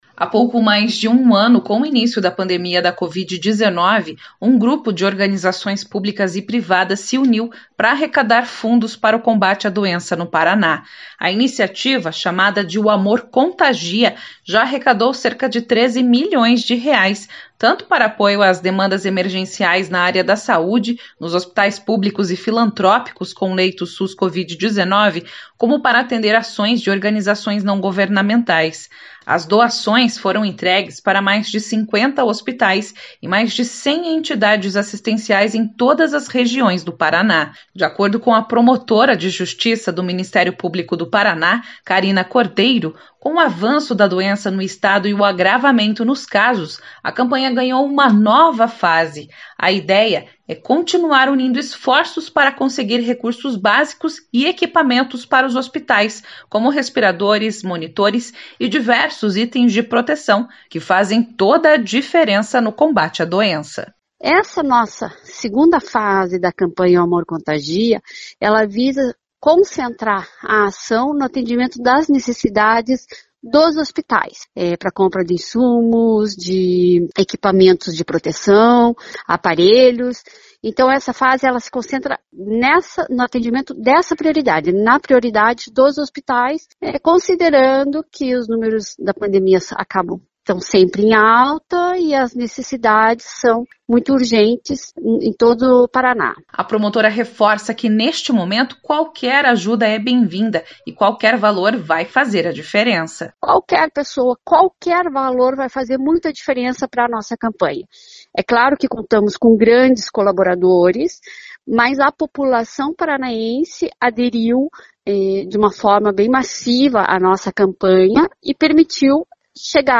Em mais uma reportagem da série “Vale Muito” vamos saber mais detalhes de uma ação que ajuda no combate à Covid-19. A campanha já arrecadou mais de R$ 13 milhões para a compra de equipamentos, cestas básicas e insumos e itens de segurança.